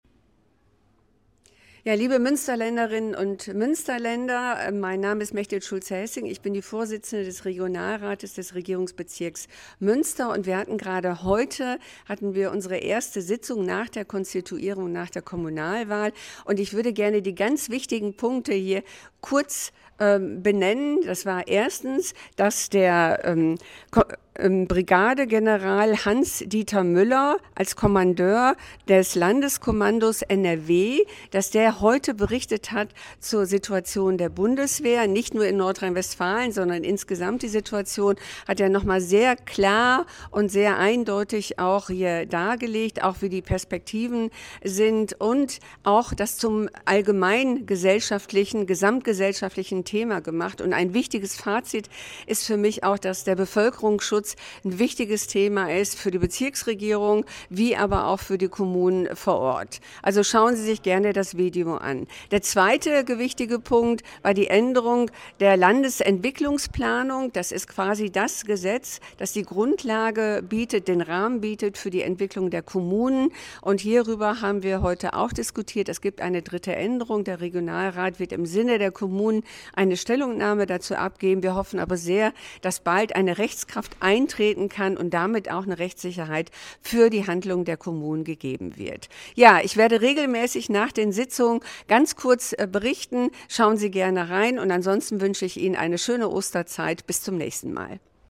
O-Ton der Regionalratsvorstizenden Mechtild Schulze Hessing